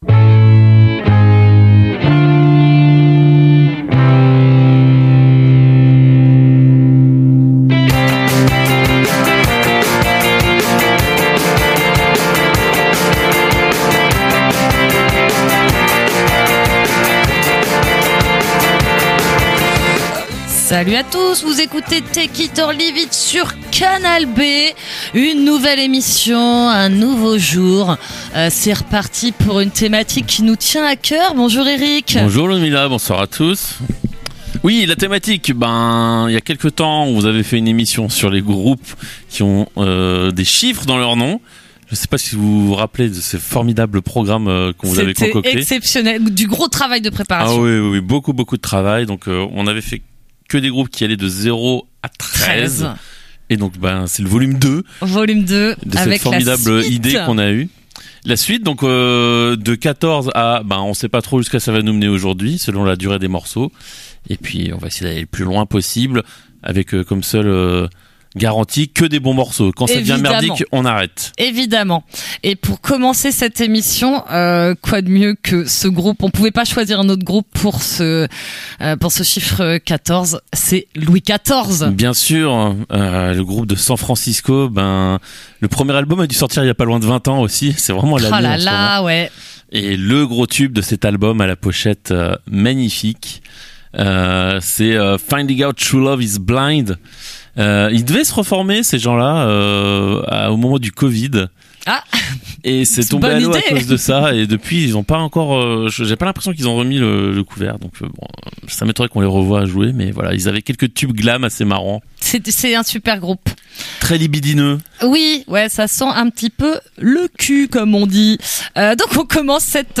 Une émission rock’n’roll, dans son contenu comme dans sa façon d’aborder la musique. Au programme : actu, chroniques, interviews, débats et mauvaise foi.